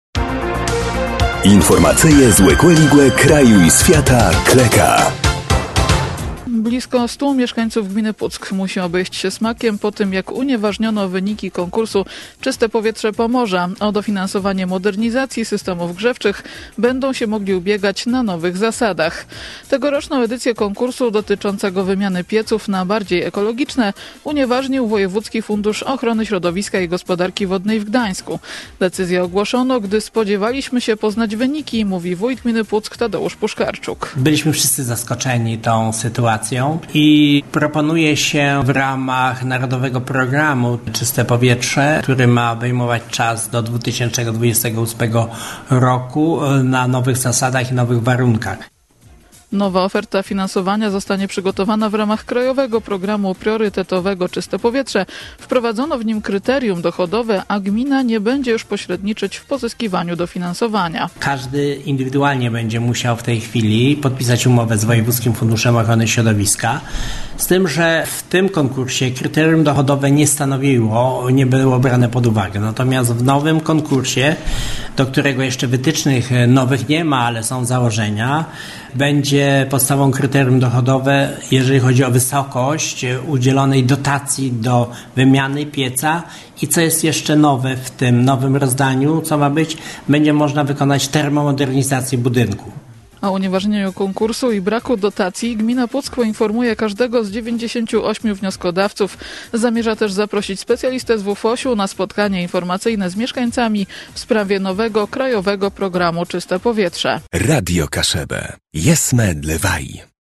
– Decyzję ogłoszono, gdy spodziewaliśmy się poznać wyniki – mówi wójt gminy Puck Tadeusz Puszkarczuk.